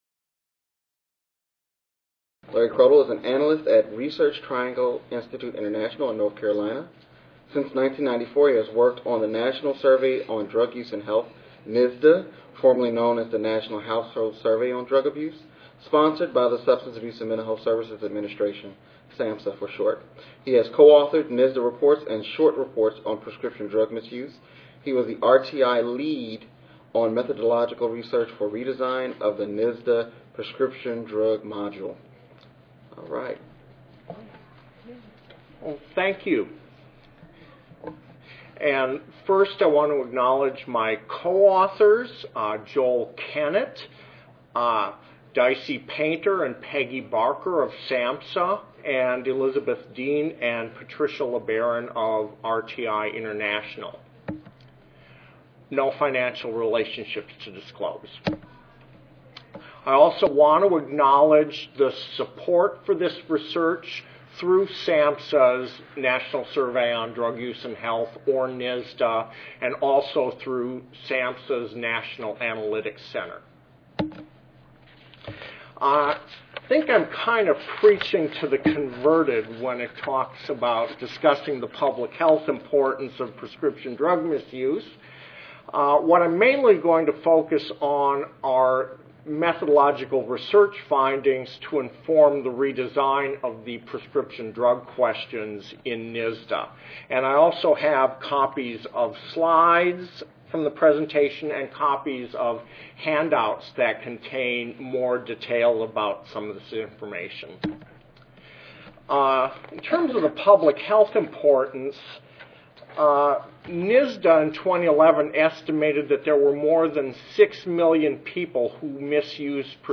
This presentation will discuss findings from a focus group, usability testing, and cognitive interviews as part of an effort to redesign the prescription drug questions in NSDUH. Findings will be presented on respondents' ability to identify prescription drugs they have used, to differentiate between legitimate use and misuse based on the revised questions, and (where applicable) to identify specific behaviors they engaged in that constitute misuse.